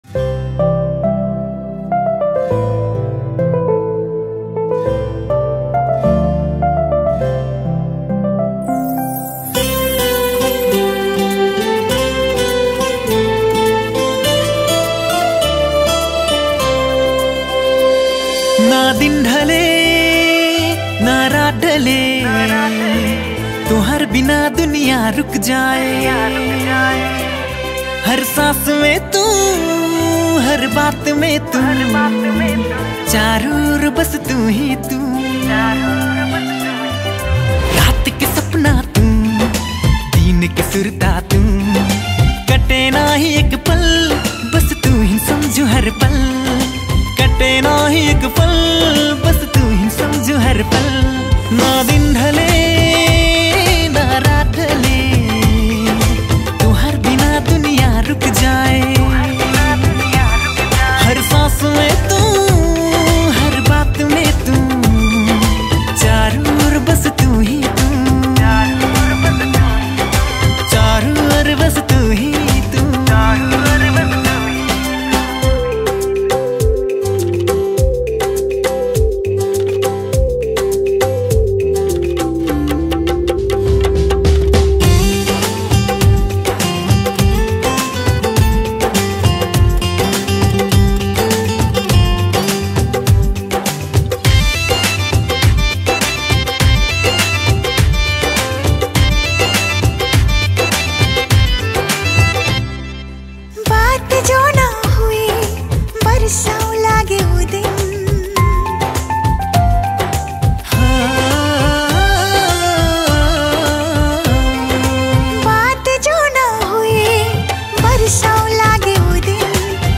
ACOUSTIC RECORDING STUDIO (KTM)